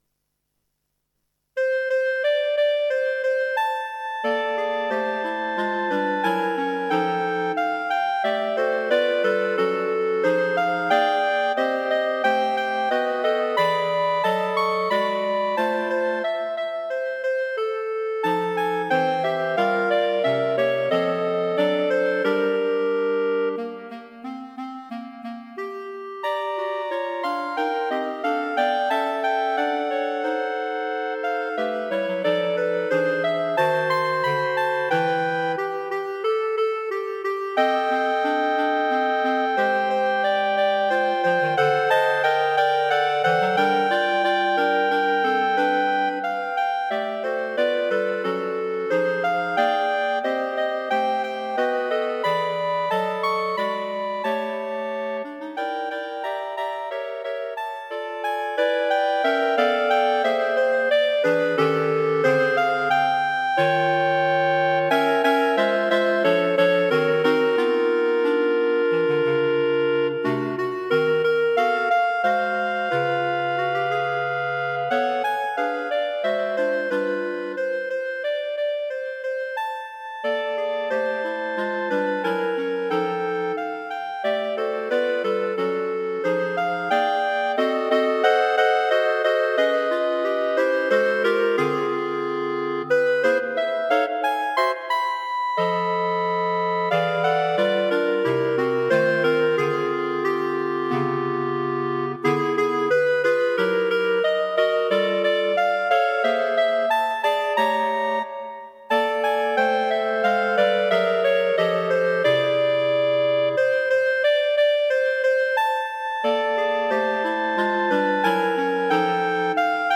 Clarinet Quartet
A suite in a light style, from swing to mellow jazz.
Arranged for three Clarinets in Bb, and Bass Clarinet.